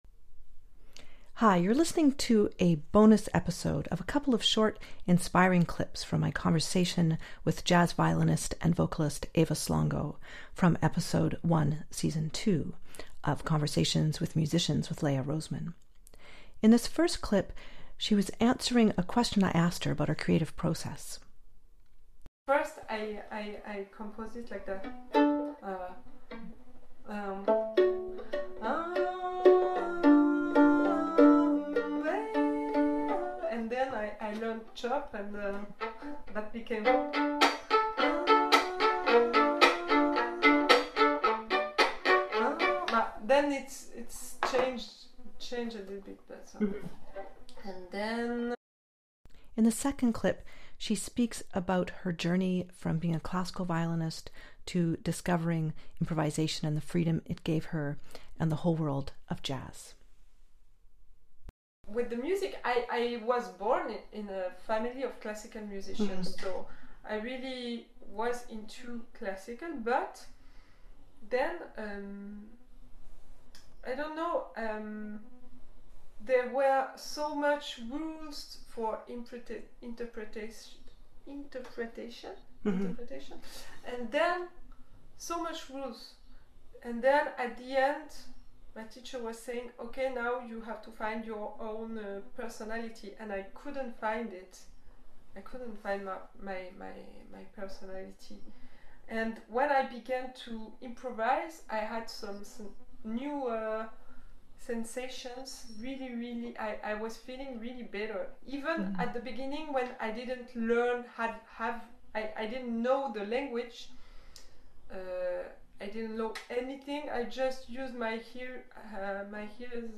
In this short bonus episode you'll hear short clips from my conversation with jazz violinist and vocalist